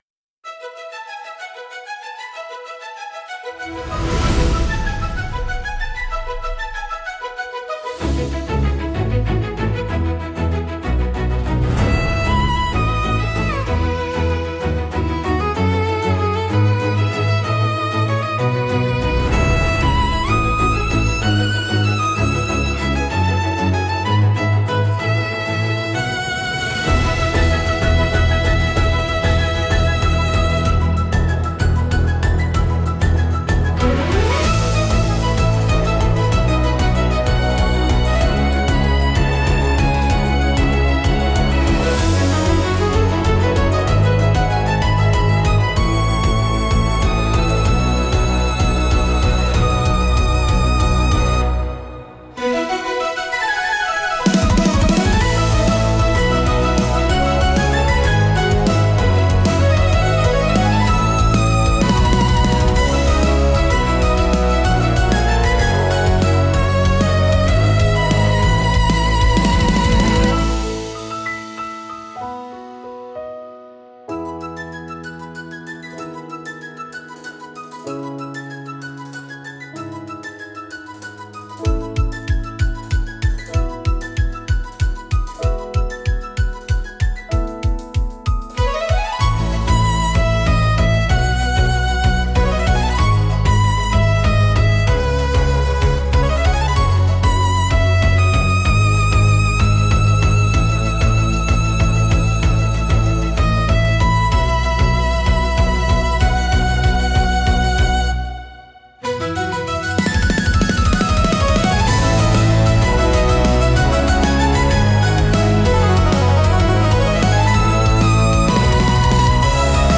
優雅なストリングスと力強いブラスが重なり合い、広がるコーラスが新たな始まりの高揚感と希望をドラマチックに表現します。